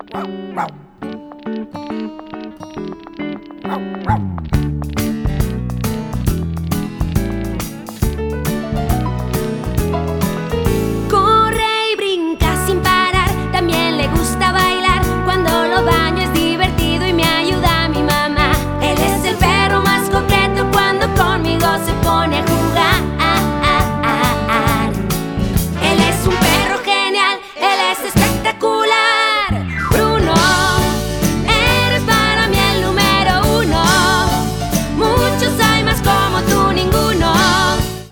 In 2007, she released another new children's album.